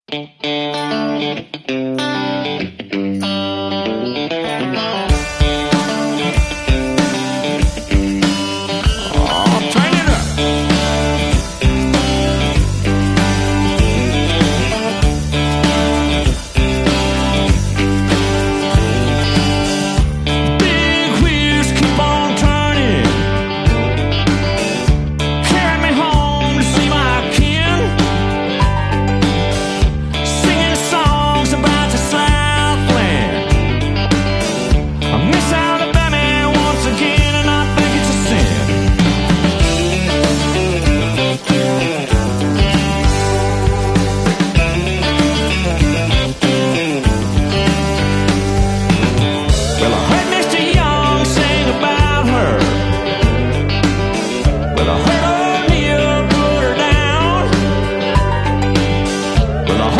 Oldtimer Treffen in Wolfenbüttel auf